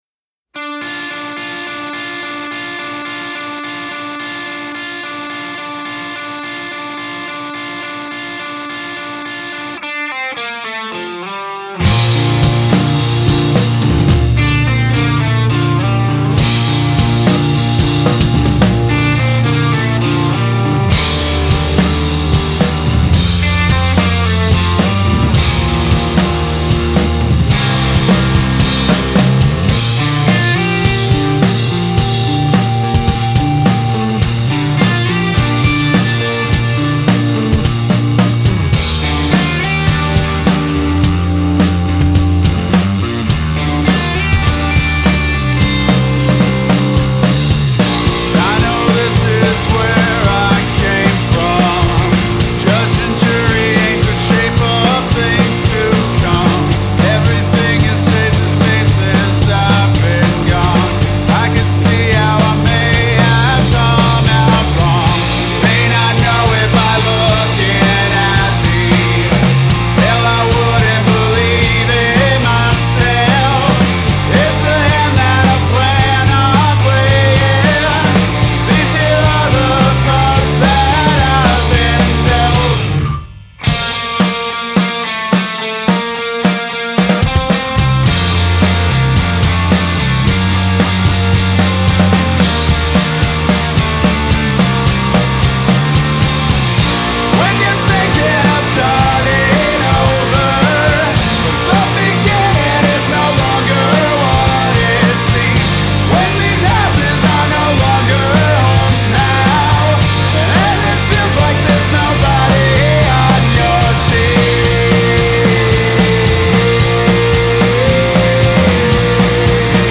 Guitars, Vocals, Keys
Drums
Bass
Cello
Trombone